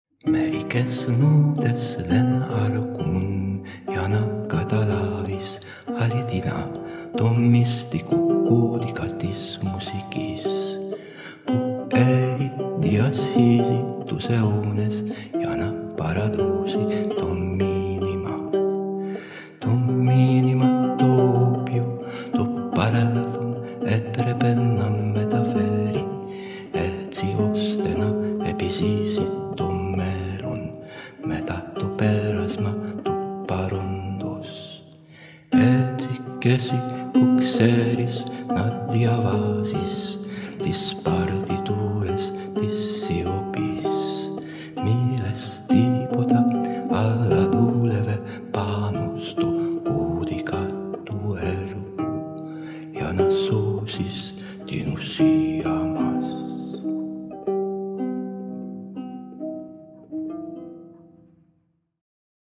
ανοιχτή πρόβα
Νίκος Λυγερός ποίηση